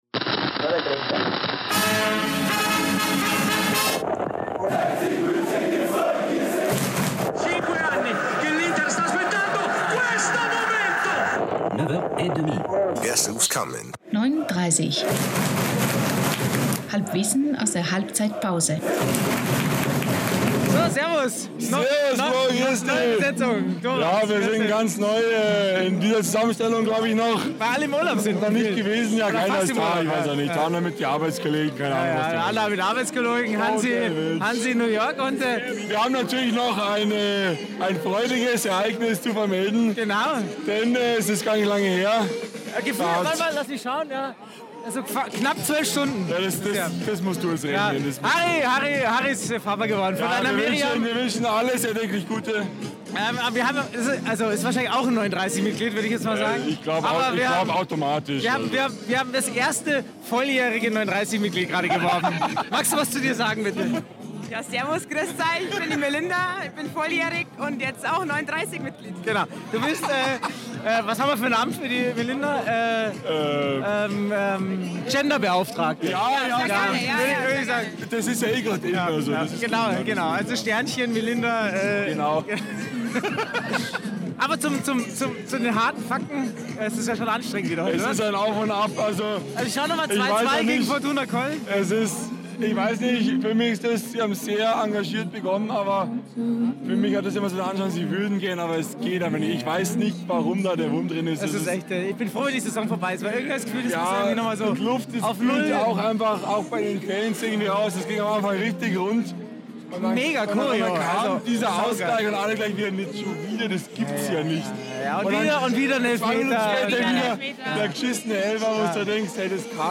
Diesmal nur mit neun30 Rumpftruppe im Stadion.
Der Podcast aus der Westkurve im Grünwalderstadion bei den Spielen des TSV 1860.